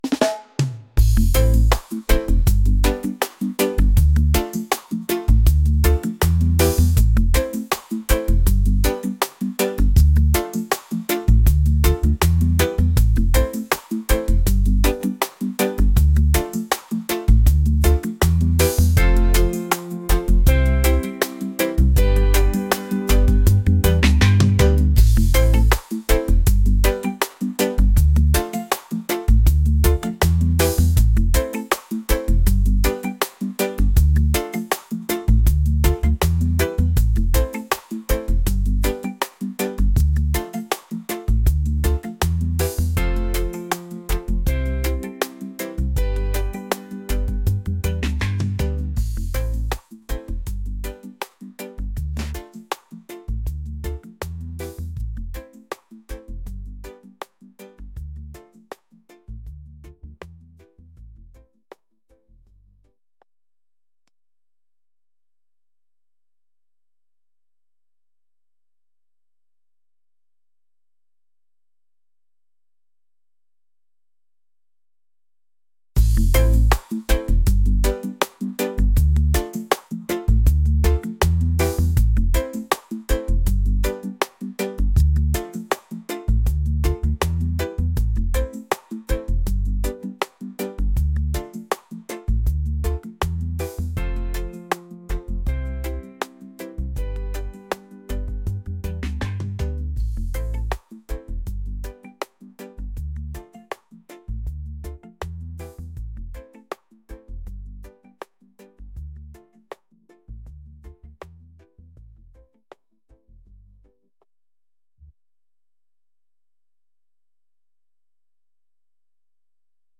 smooth | reggae | laid-back